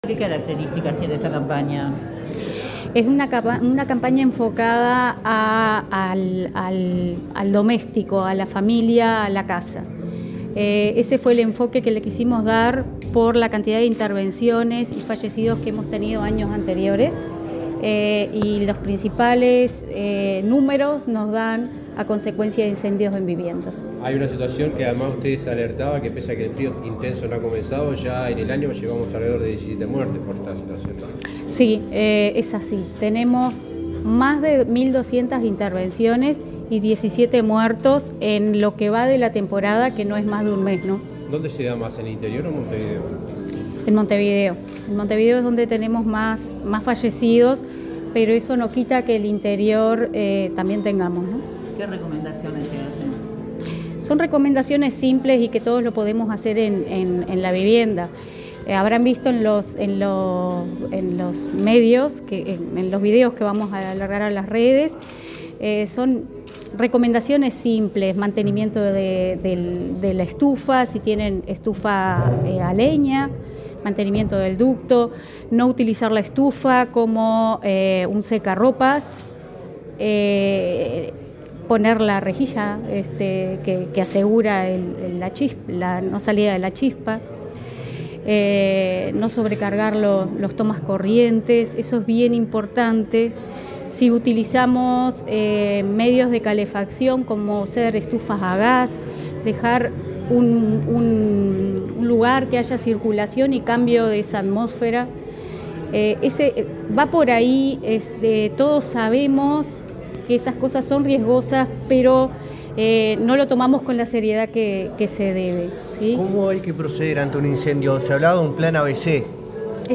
Declaraciones de la directora de Bomberos, Valeria Vasconcellos
En el lanzamiento de la campaña de prevención de incendios en invierno “Prevenir salva vidas”, realizó declaraciones a la prensa la directora nacional